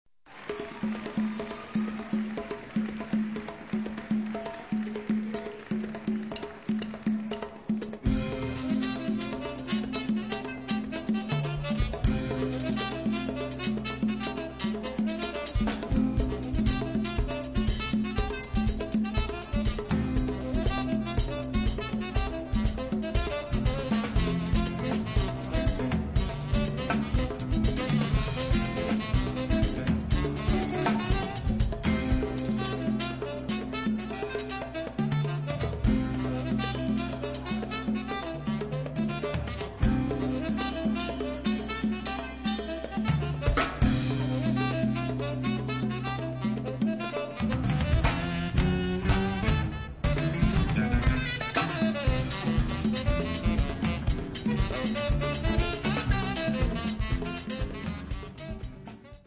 Guitars
Bass